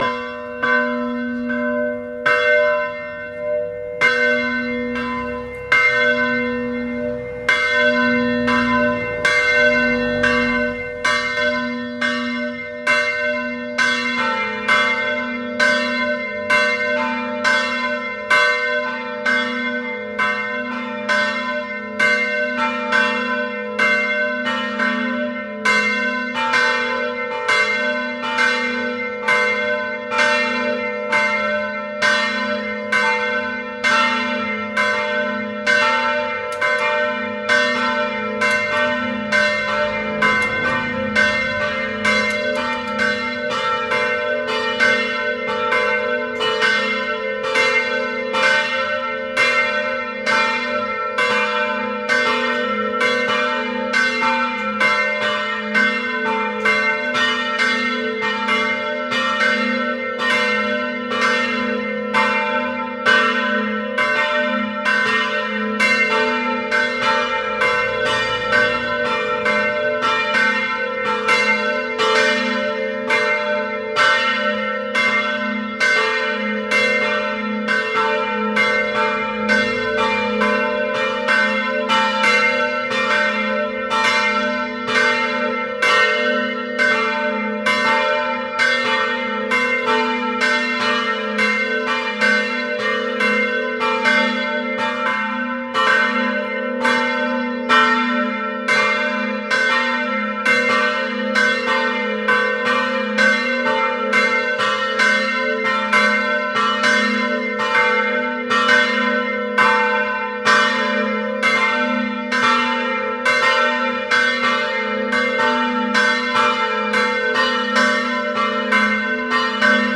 (Pozn. Zvony jsou ulity ze slitiny mědi (78%) a cínu (22%).
Zvuk troubeckých zvonů při slavnostním anděl Páně
zvony.mp3